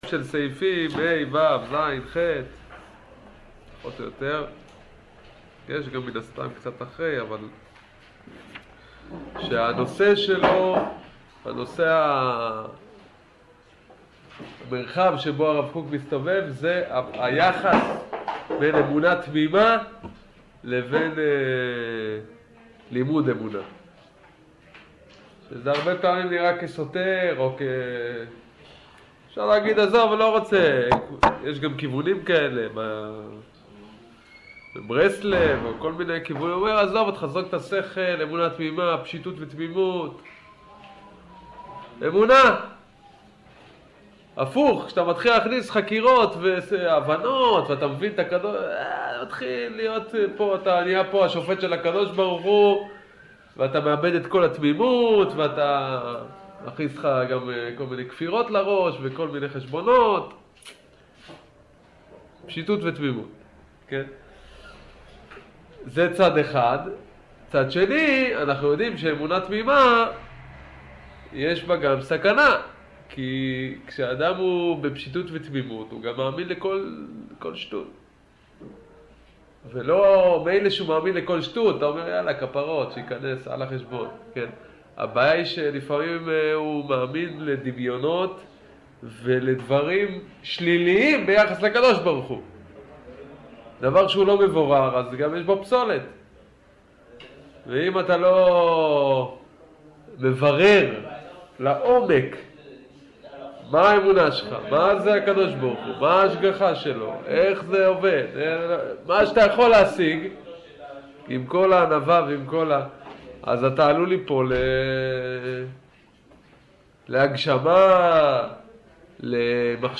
שיעור אמונה ה'